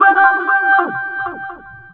VOX FX 2  -L.wav